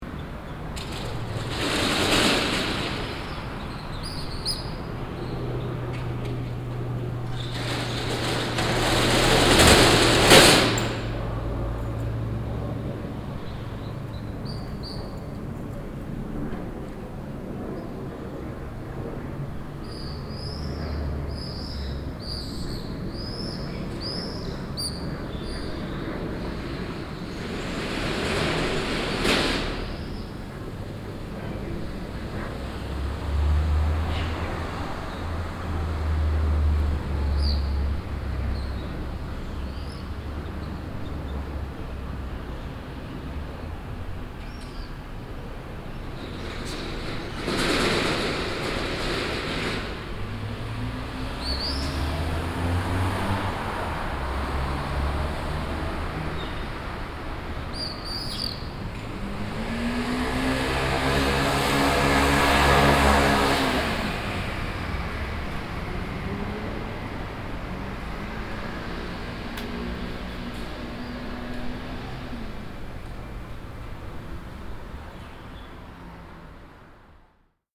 Rumore
Sono le 19:30 e in città chiudono i negozi Torino
Microfoni binaurali stereo SOUNDMAN OKM II-K / Registratore ZOOM H4n